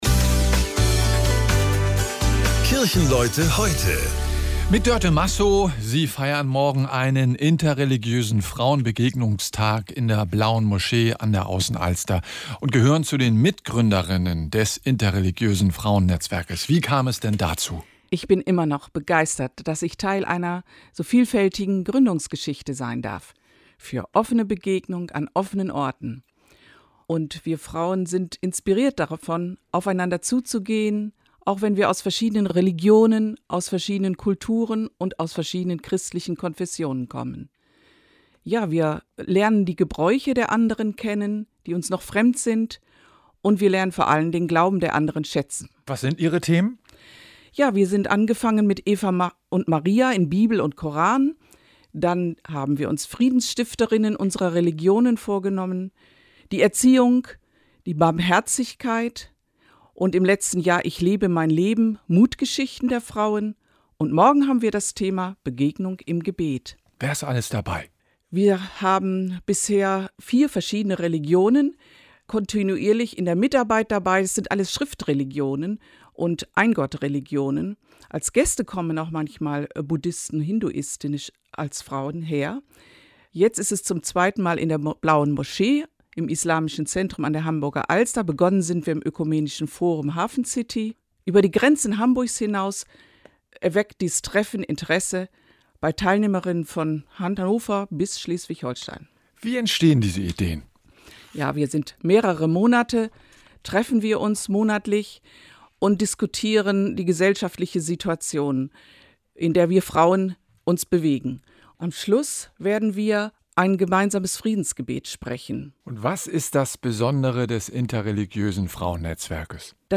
NDR Studiogespräch